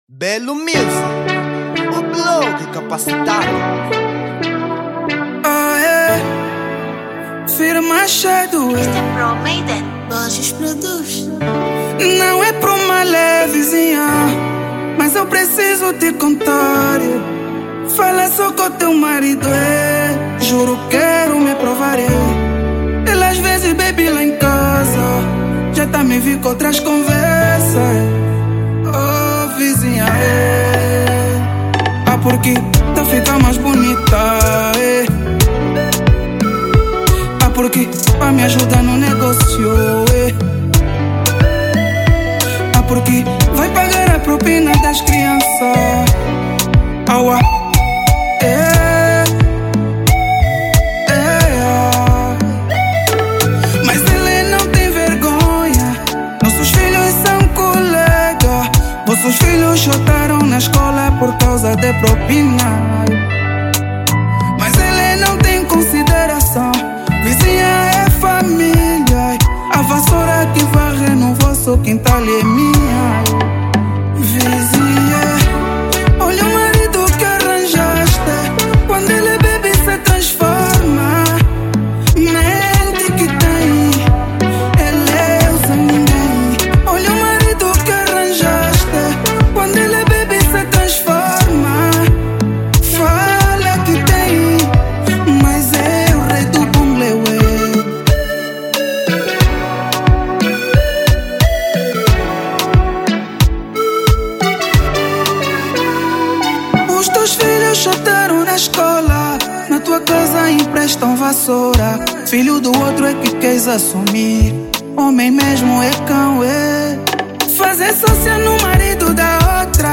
Zouk Formato